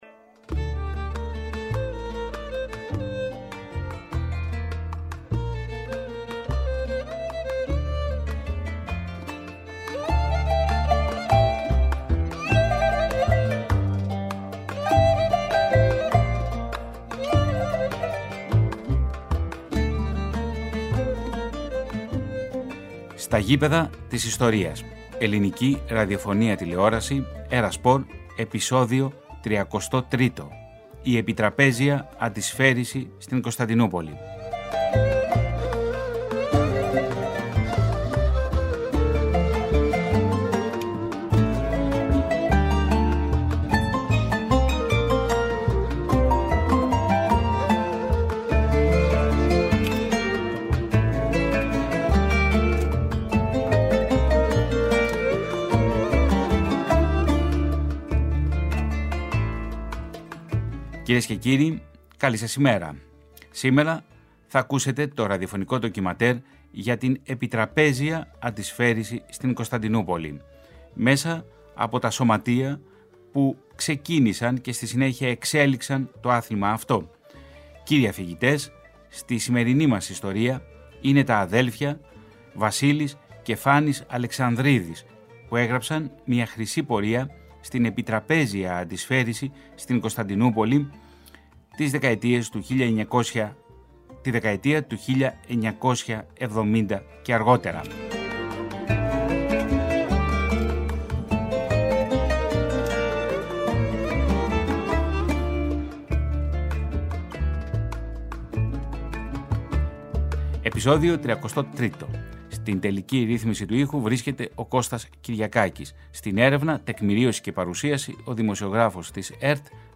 ΝΤΟΚΙΜΑΝΤΕΡ